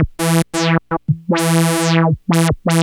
Synth 22.wav